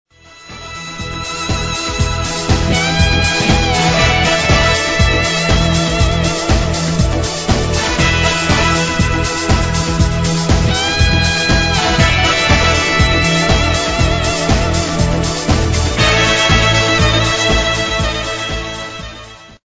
vokal pop rock, el. gitara solo